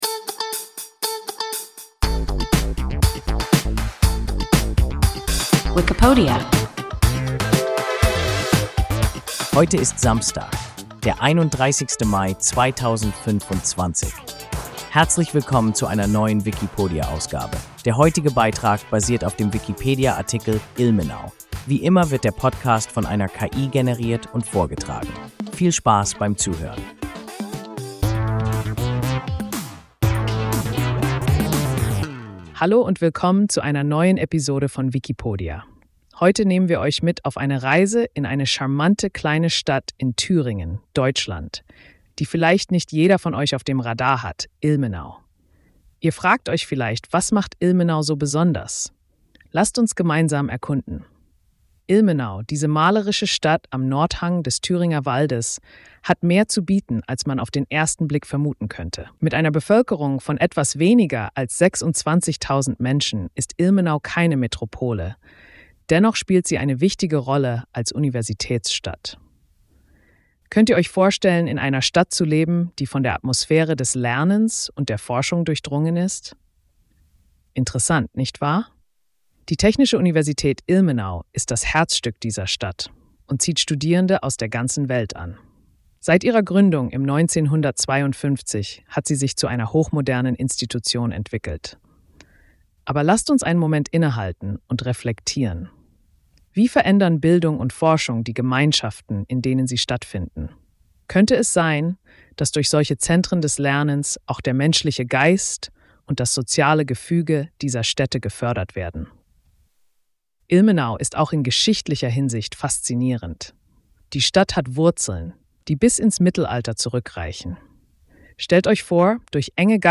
Ilmenau – WIKIPODIA – ein KI Podcast